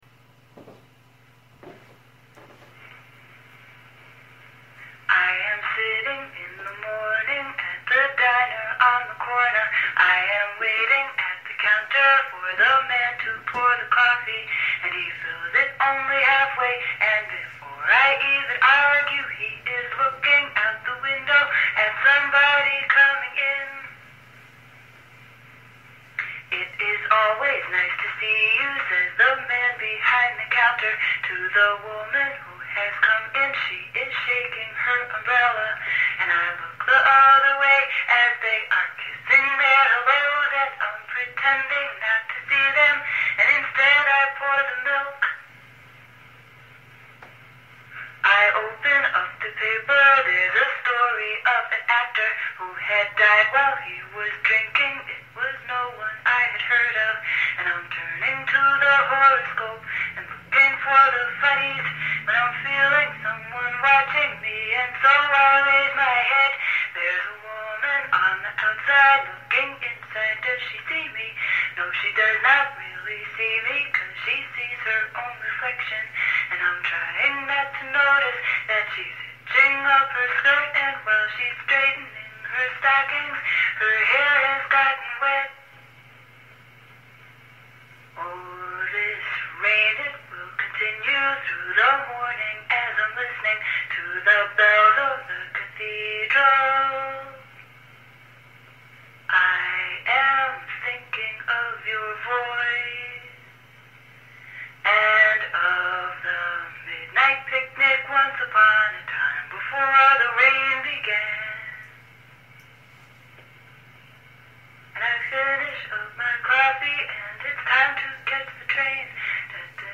a capella on Edison wax cylinder